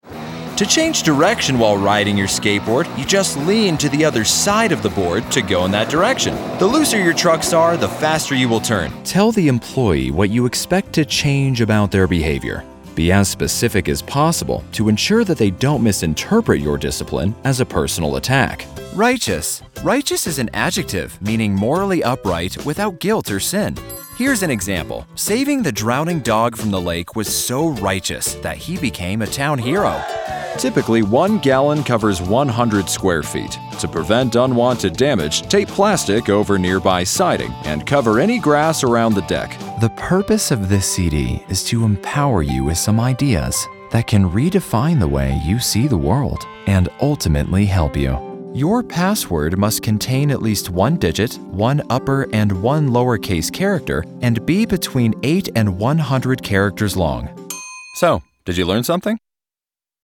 Conversational, Real, Edgy, Versatile, Professional, Cool, Hip, Compelling, Funny, Narrator
Sprechprobe: eLearning (Muttersprache):